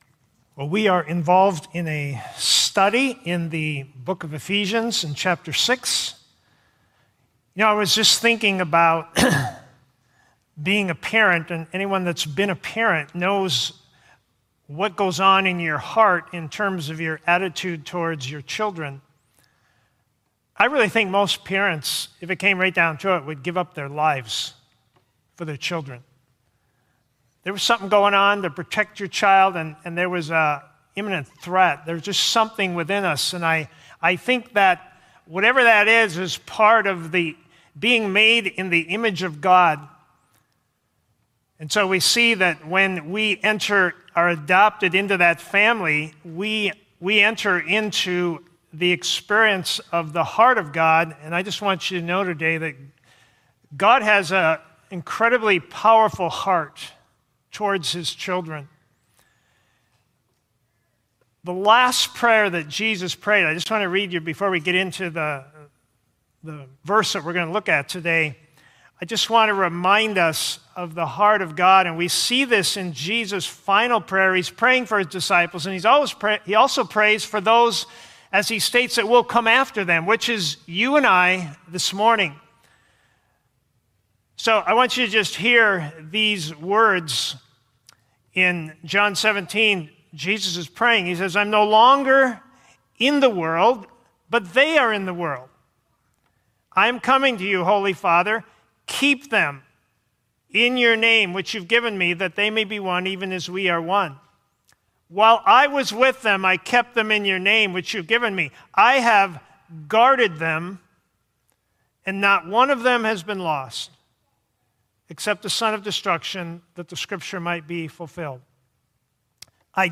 First-Baptist-Sermon-July-5-2020.mp3